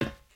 sounds / material / human / step / metal_plate2.ogg
metal_plate2.ogg